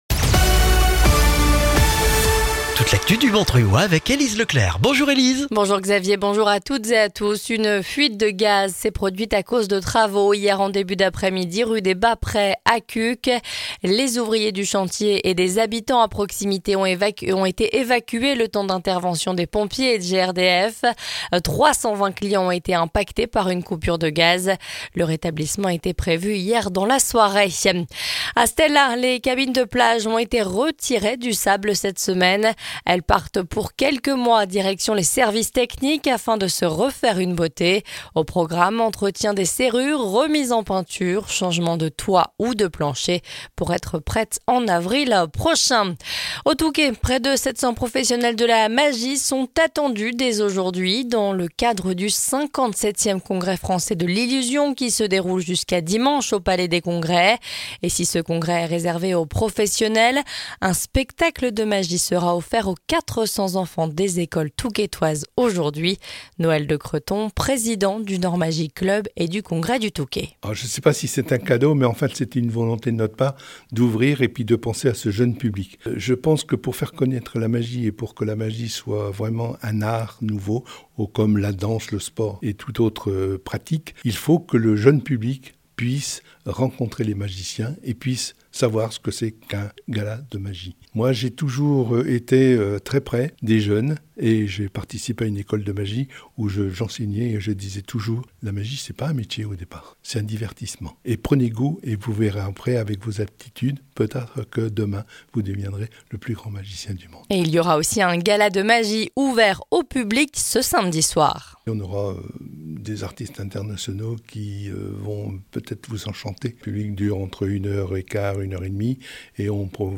Le journal du jeudi 3 octobre dans le Montreuillois